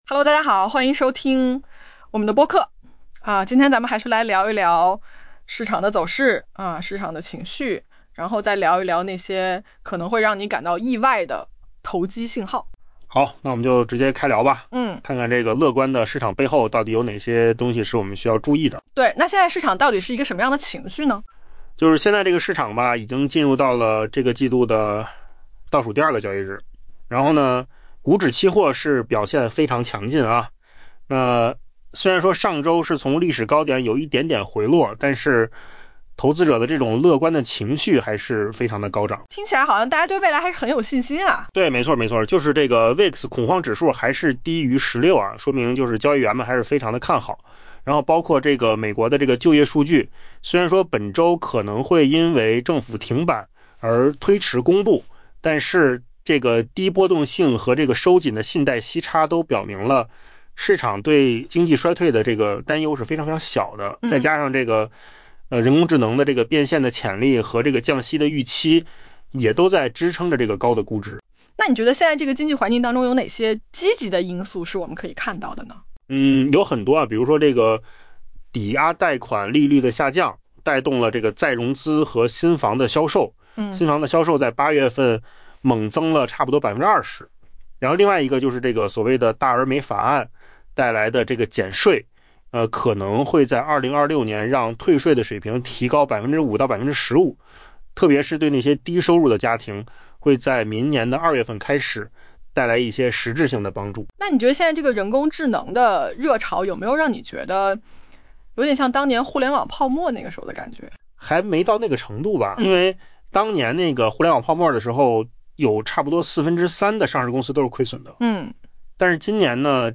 AI 播客：换个方式听新闻 下载 mp3 音频由扣子空间生成 目前，市场正进入本季度的倒数第二个交易日，股指期货显示其走势强劲，投资者持续无视上周从历史高点小幅回落的走势。